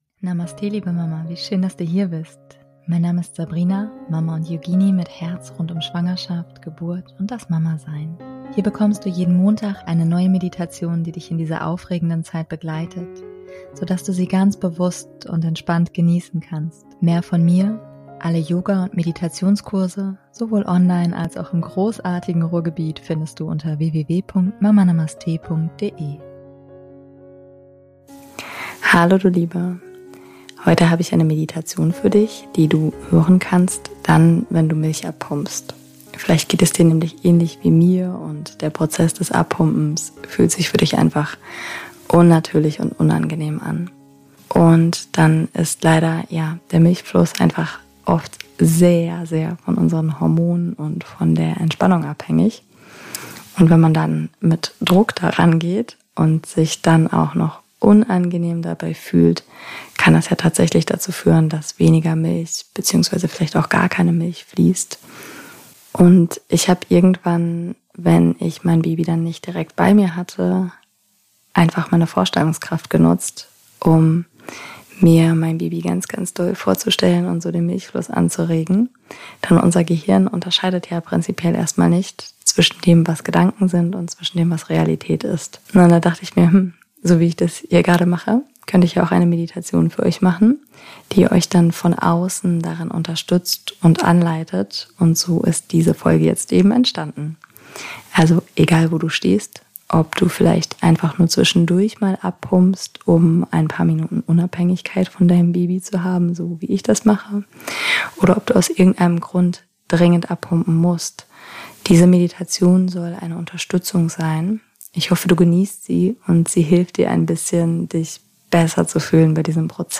#187 - Meditation zum Milch abpumpen